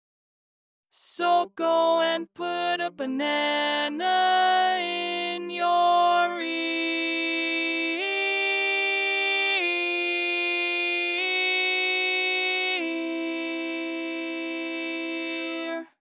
Key written in: C Major
Type: Female Barbershop (incl. SAI, HI, etc)